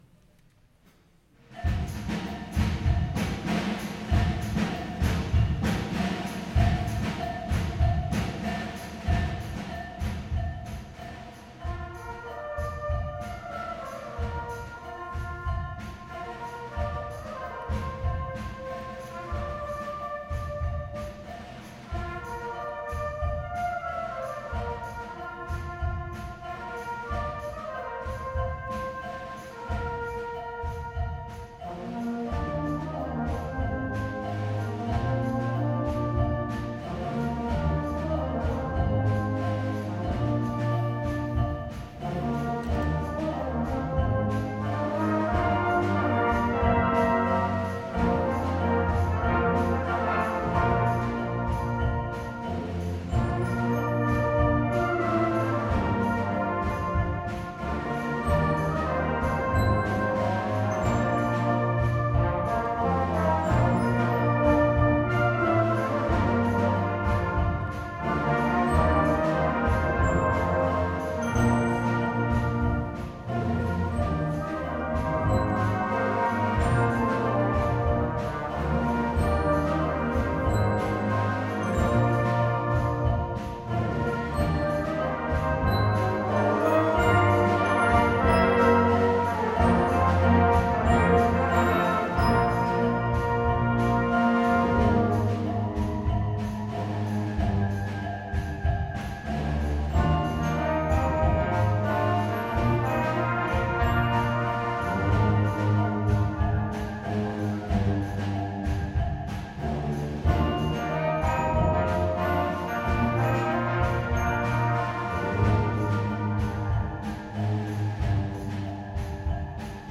Smakebiter fra konsert i �stre Porsgrunn Kirke : : Om Bamble Brass : Bamble Brass